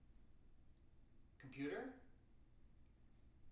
wake-word
tng-computer-355.wav